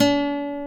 Index of /90_sSampleCDs/Roland L-CDX-01/GTR_Nylon String/GTR_Nylon Chorus